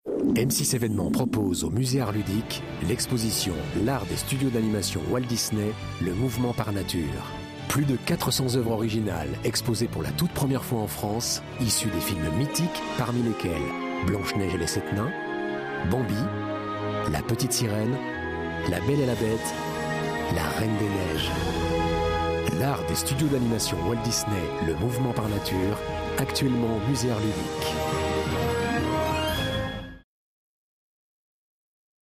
Genre : Voix-off